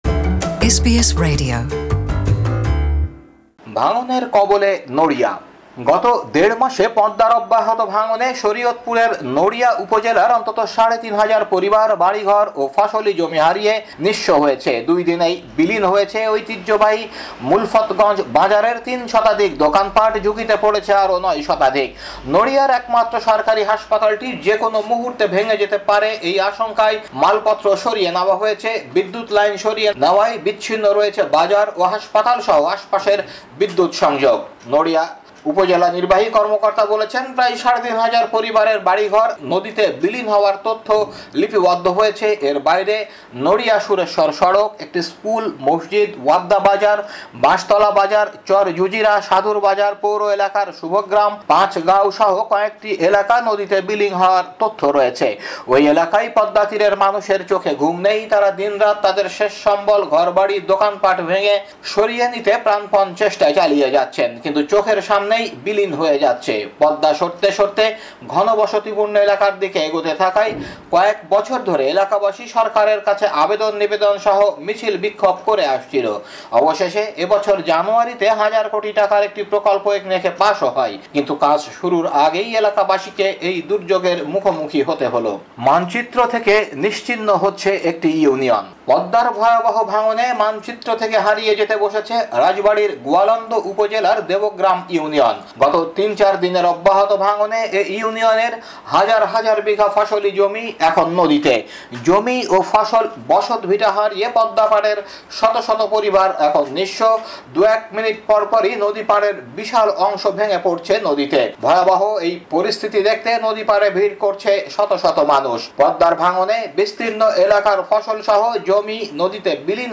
বাংলাদেশী সংবাদ বিশ্লেষণ: ৭ সেপ্টেম্বর ২০১৮